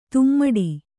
♪ tummaḍi